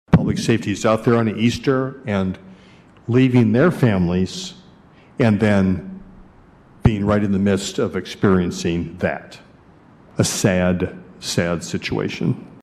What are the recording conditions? City commissioners commented on the city’s third homicide of the year last night.